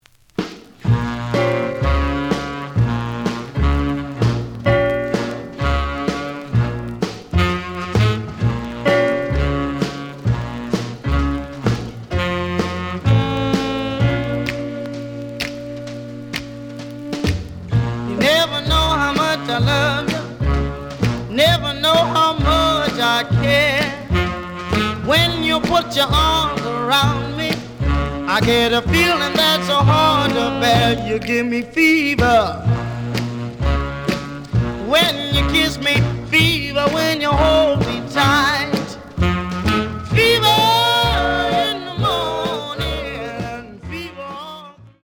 試聴は実際のレコードから録音しています。
●Genre: Rhythm And Blues / Rock 'n' Roll
A面の最後とB面の前半に傷によるノイズがあるが、全体的にはおおむね良好。)